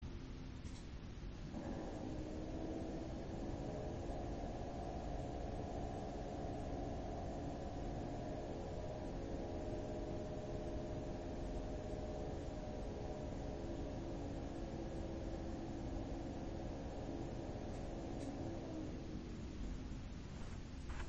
Zwei der drei Lüfter haben aus meiner Sicht zu viel Lagergeräusche.
Ich habe anbei ein paar Aufnahmen gemacht (ca. 20cm Abstand):
• 1x alle Fans zusammen bei ~1000rpm / 30%.
fans alle 1000rpm.mp3